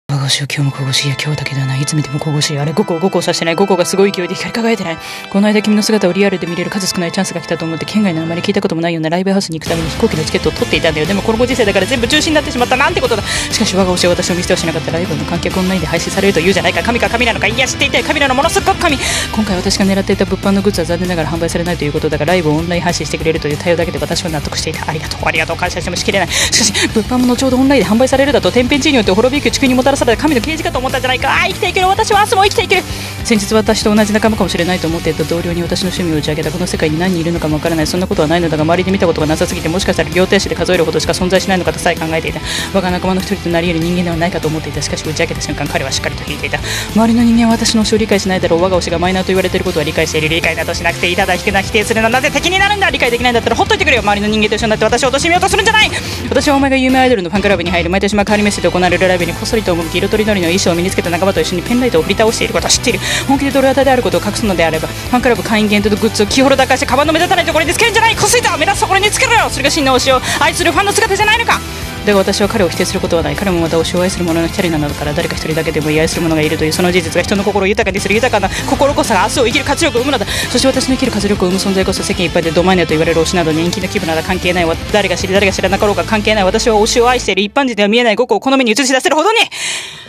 【声劇】あぁ、推しよ愛しき我が推しよ